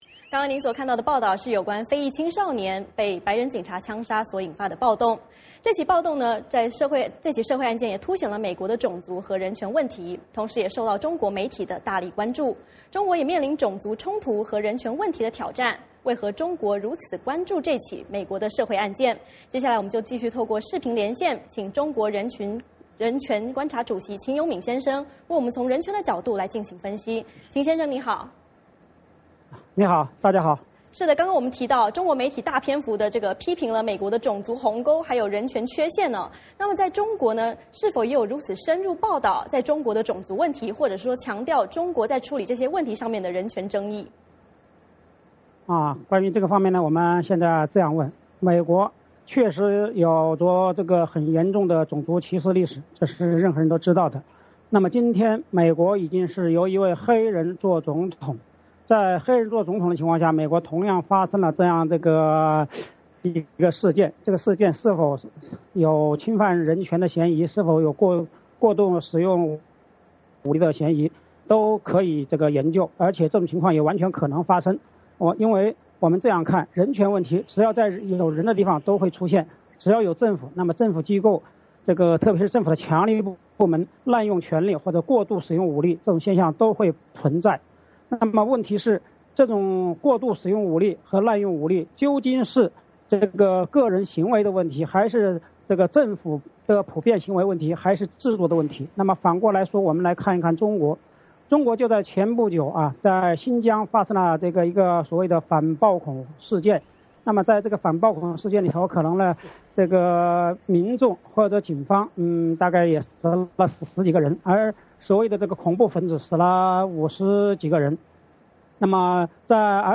美国密苏里州弗格森镇白人警察枪杀非裔青少年所引发的暴动还在升温，这起社会案件突显出了美国的种族和人权问题，同时也受到中国媒体的大力关注，中国也面临种族冲突和人权问题的挑战，为何中国如此关注这起美国案件。我们继续透过视频连线